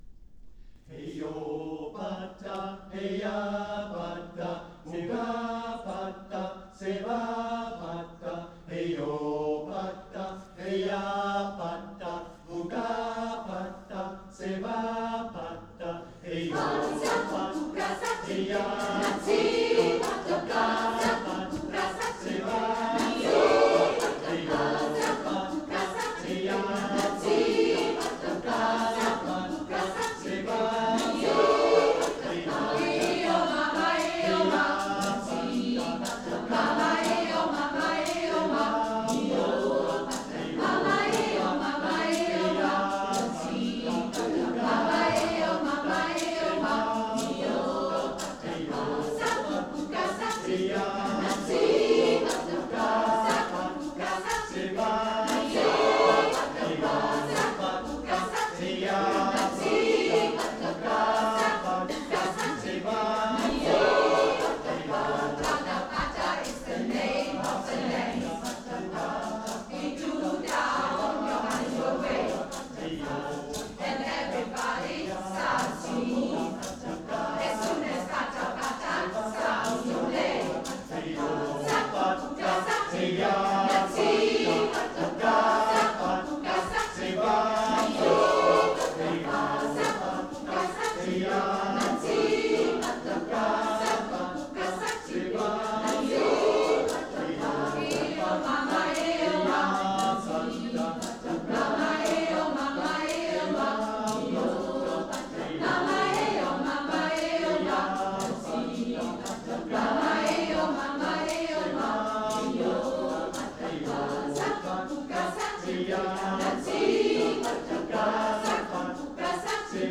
09 - Konzertaufnahmen - ChorArt zwanzigelf - Page 5
Kathy Kelly mit ChorArt zwanzigelf 17.03.2019 – Pata Pata